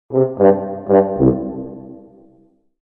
Low Fail Horn Melody Sound Effect
Description: Low fail horn melody sound effect. Use this short melodic horn in a low register as a failure sound for games, quizzes, cartoon clips, incorrect answers, video endings, Reels, or any multimedia project. This gentle, funny horn adds a playful and cinematic touch to your videos, social media posts, and branding content.
Low-fail-horn-melody-sound-effect.mp3